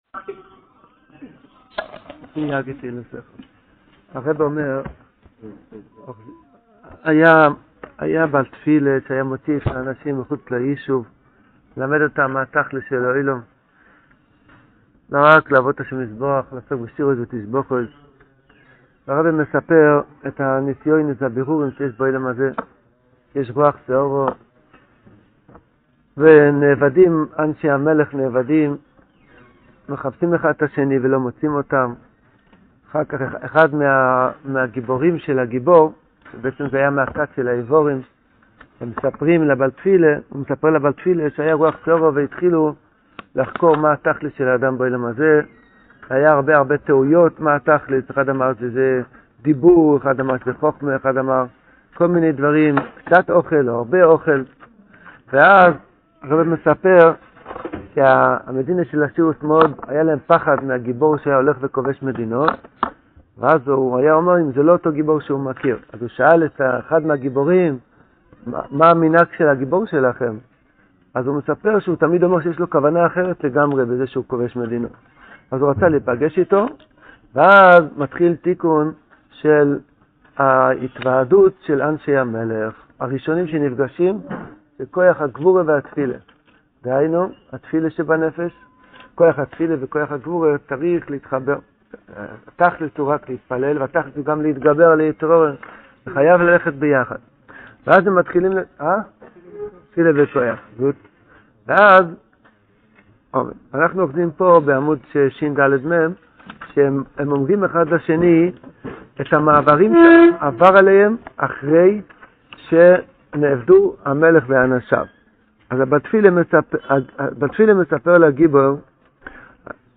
This shiur is given daily after shachris and is going through each of the stories in sipurei maasios in depth. The audio quality gets better after episode 26.